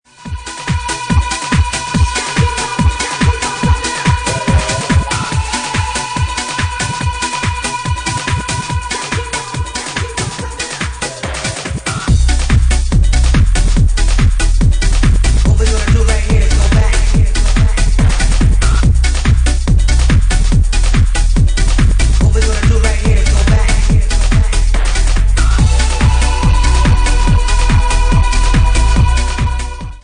Bassline House at 143 bpm